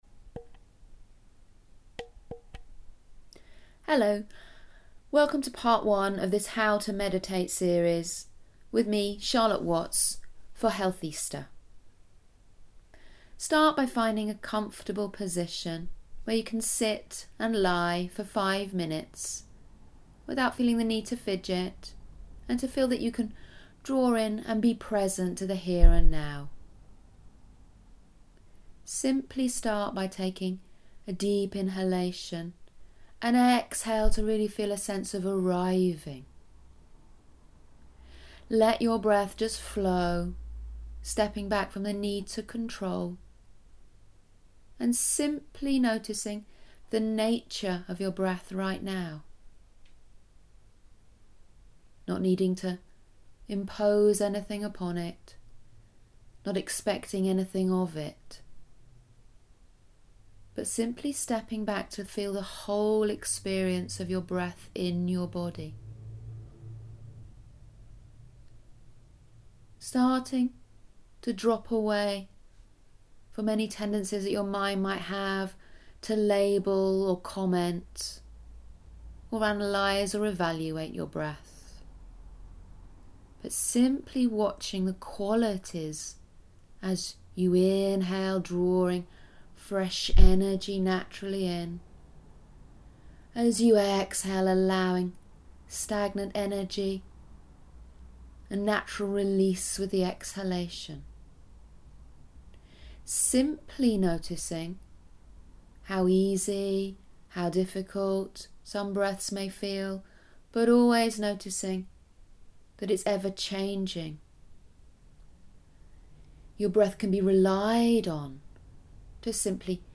You will be given an audio mediation each week to guide and support you.
Week 1 Guided Meditation: Mindfulness of Breathing
Healthista-How-to-Meditate-1-Mindfulness-of-Breathing.mp3